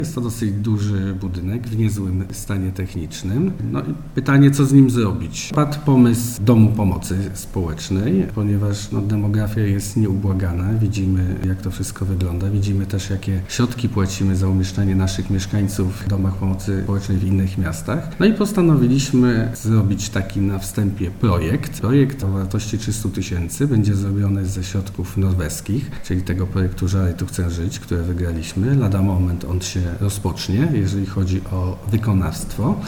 – Podstawą do dalszych prac jest przygotowanie projektu – mówi wiceburmistrz Żar Olaf Napiórkowski: